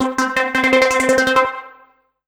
14SYNT01  -R.wav